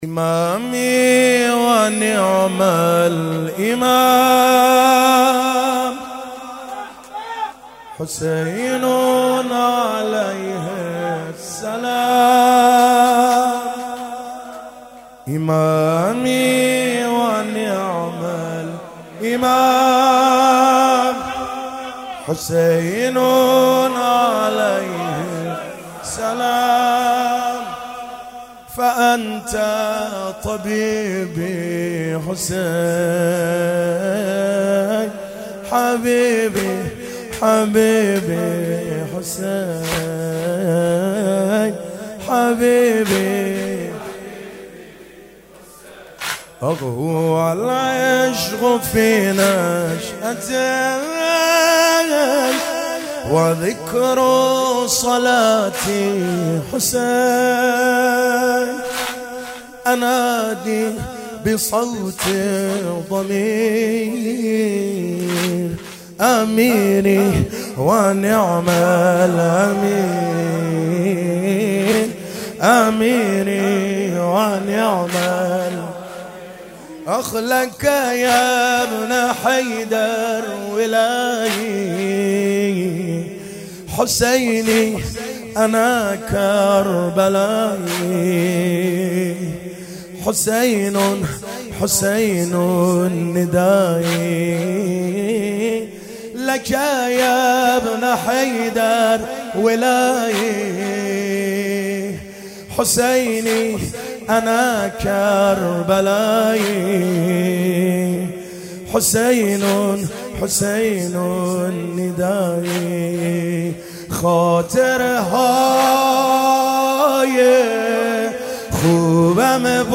مناسبت : دهه اول صفر